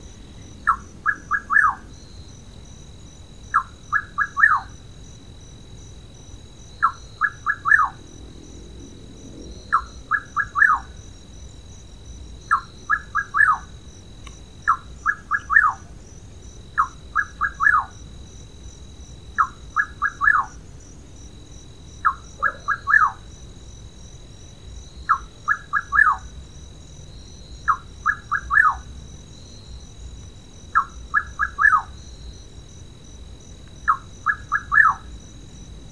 Atajacaminos Colorado (Antrostomus rufus)
Nombre en inglés: Rufous Nightjar
Fase de la vida: Adulto
País: Brasil
Condición: Silvestre
Certeza: Vocalización Grabada